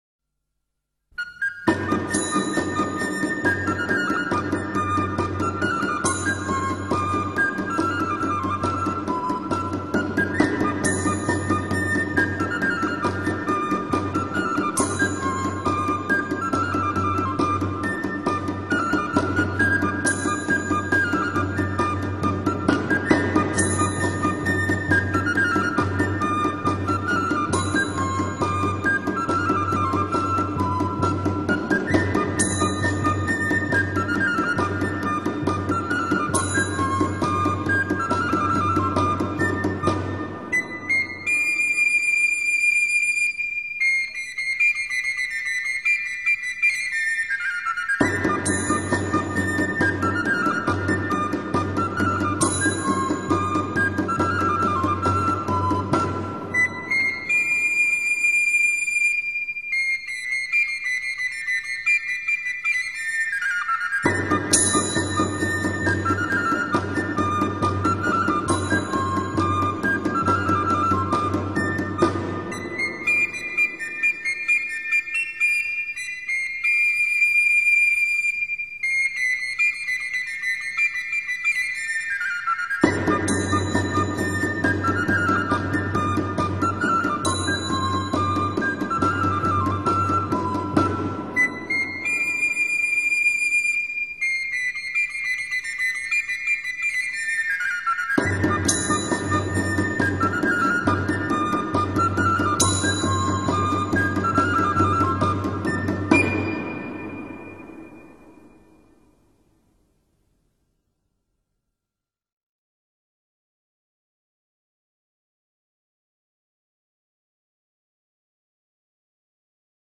Musica Medieval da Corte (1)
Musica-Medieval-da-Corte-1.mp3